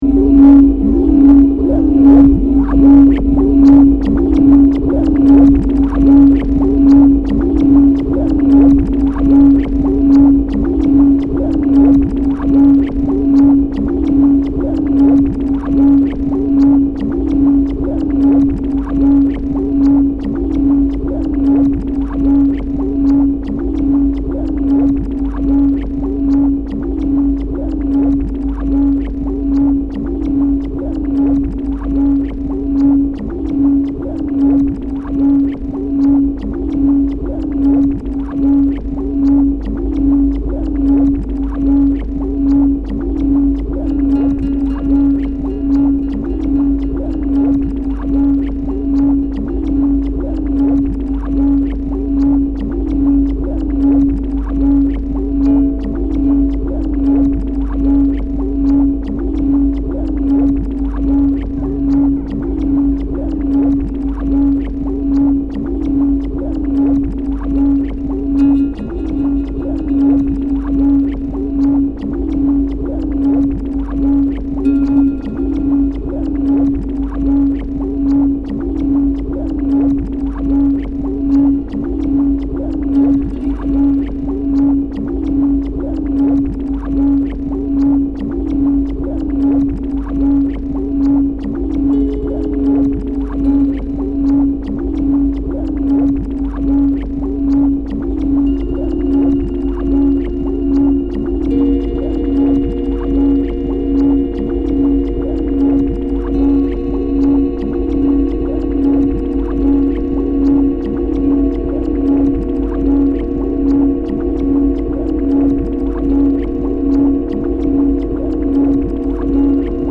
Guitar_02_Resonence.mp3